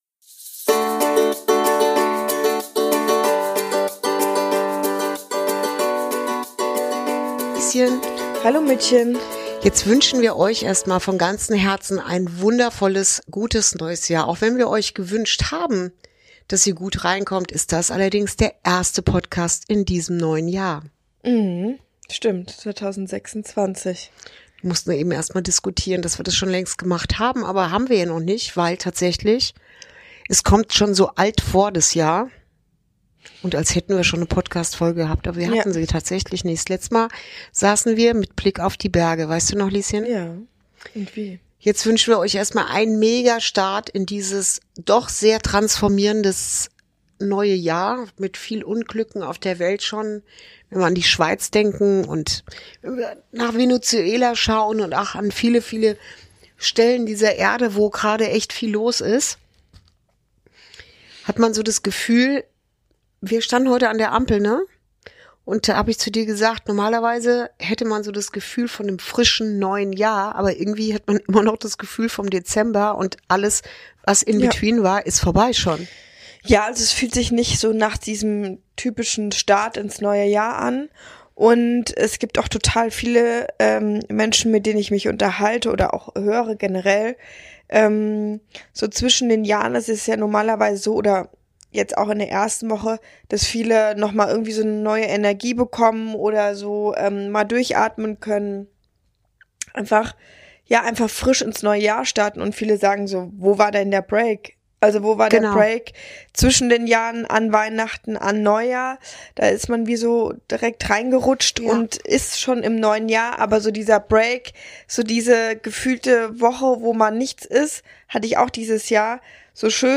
Folge 47: Ein schneller Start ins Jahr 2026 ~ Inside Out - Ein Gespräch zwischen Mutter und Tochter Podcast